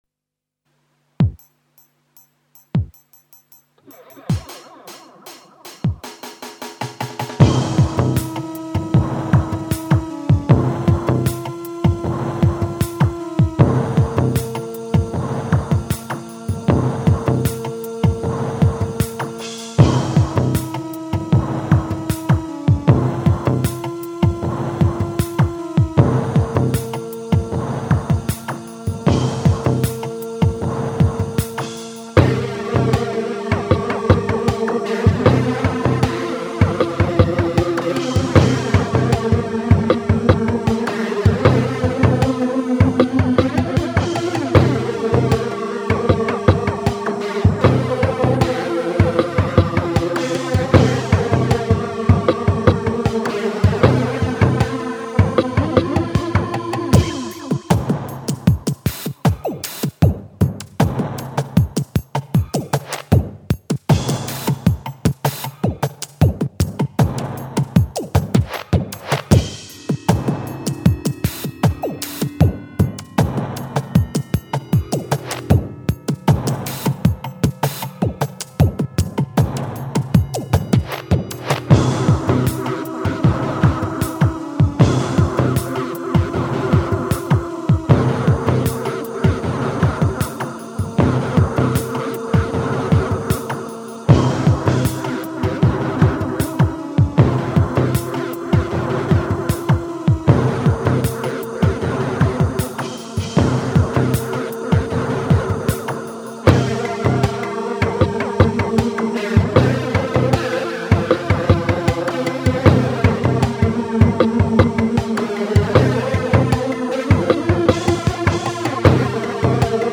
Quirky mid-tempo techno